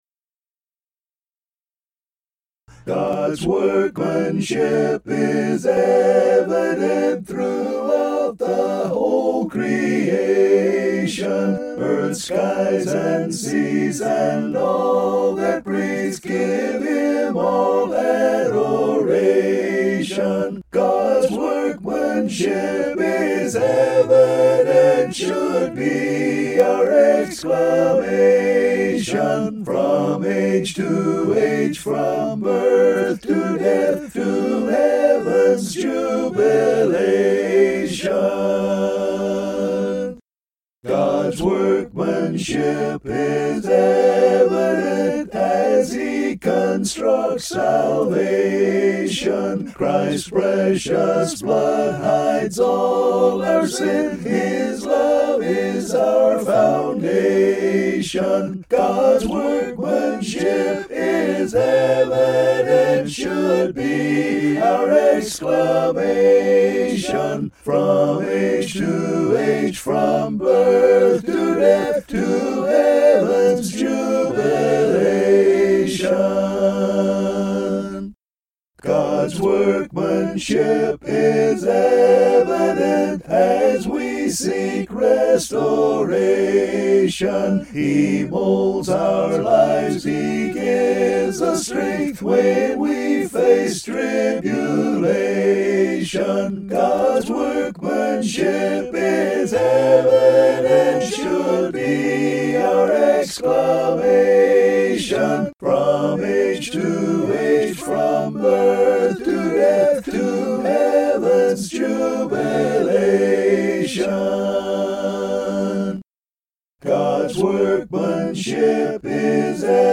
(An original hymn)